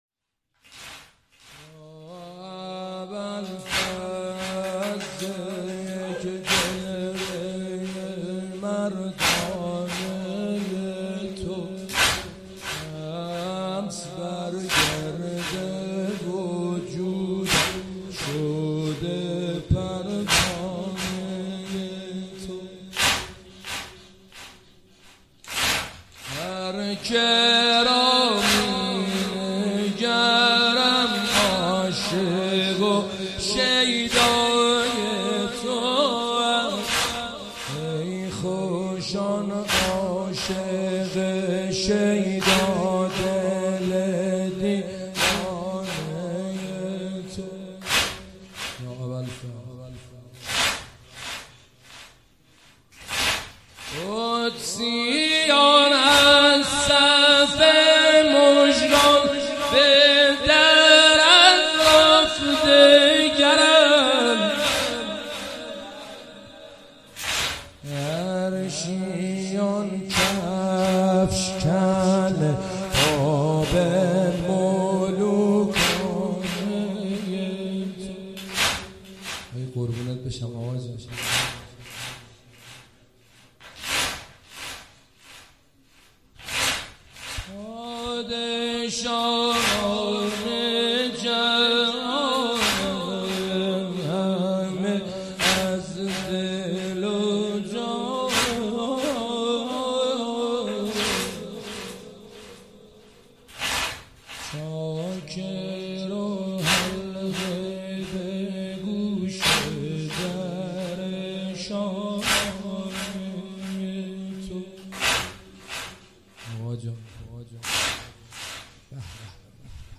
مداحی جدید حاج حسین سیب سرخی شب نهم محرم۹۷ شب تاسوعا هیئت روضة‌ العباس